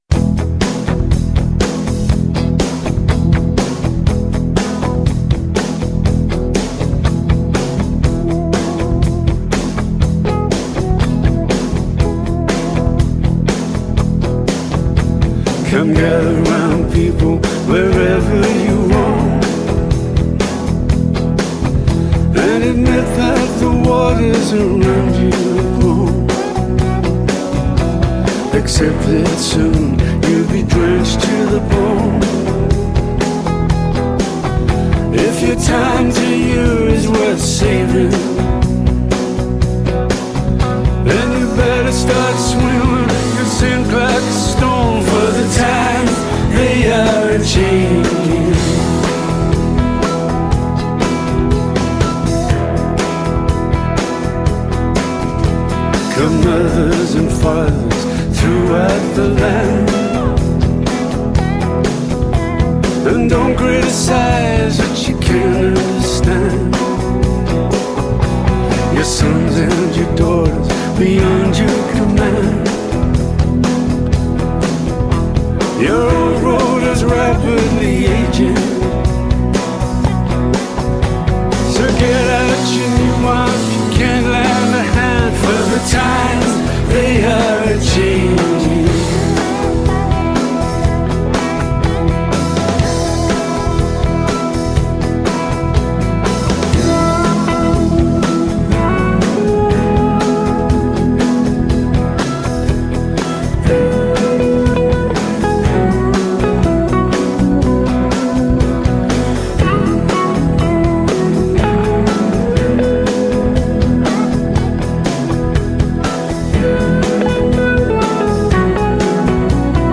αφηγείται ιστορίες των τραγουδιών πίσω από τα τραγούδια και ιστορίες των μουσικών πίσω από τη μουσική.